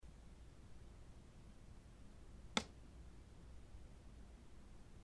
Right Bedroom – 12:00 am
A noise is captured on one of our recorders